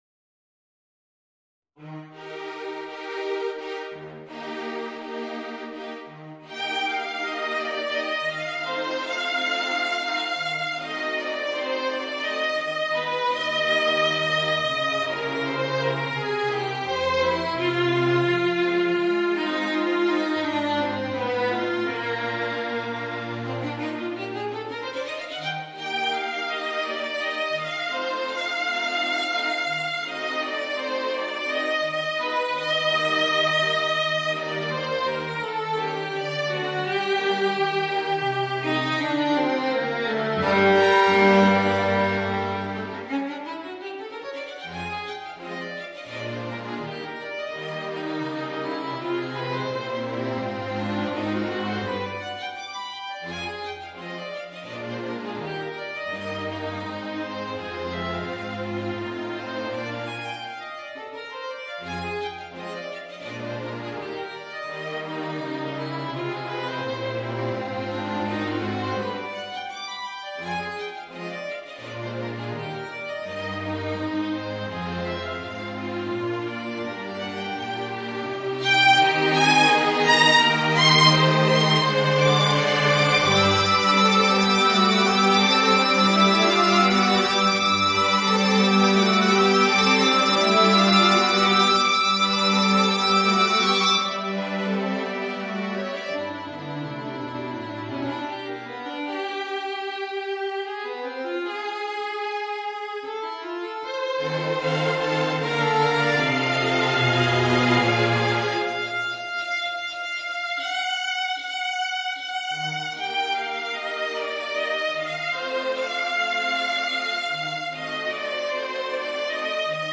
Voicing: Violin and String Quartet